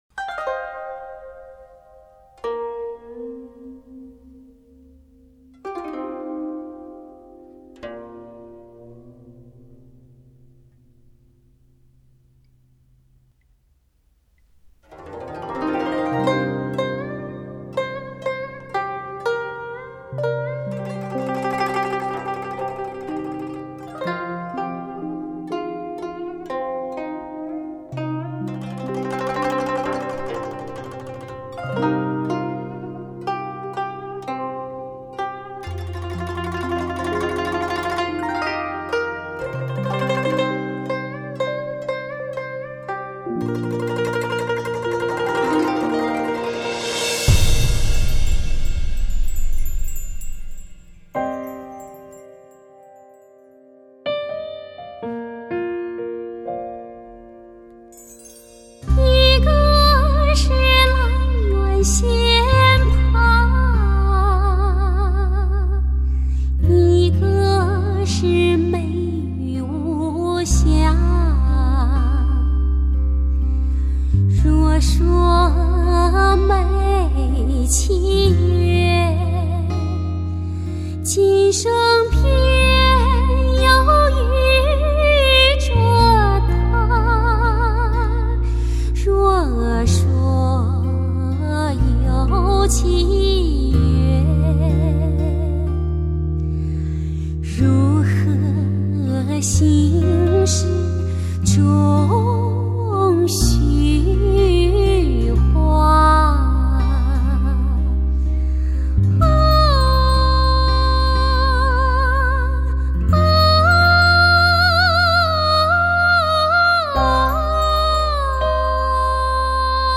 质感超凡 通透轻盈
情真意真流泻而出，透过古筝伴奏融合的和谐，
空气感下靓声音放回绕满堂，实为上乘之作。